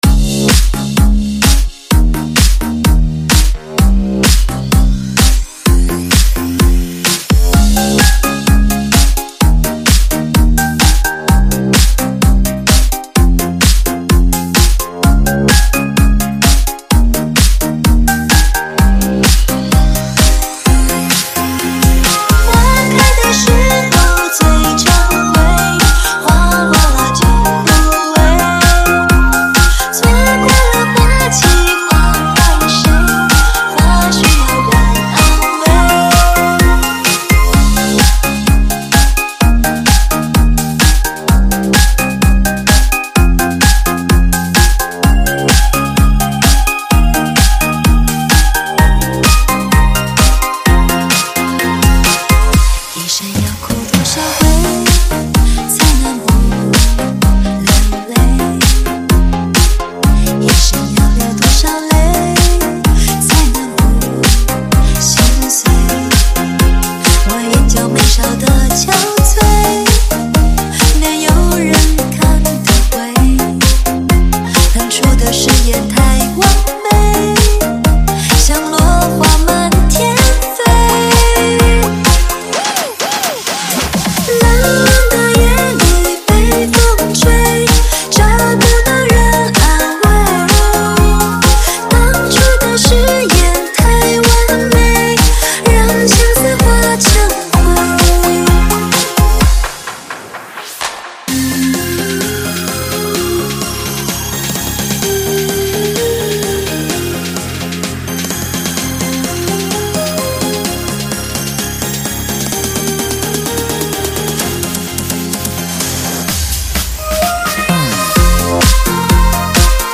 5天前 DJ音乐工程 · ProgHouse 2 推广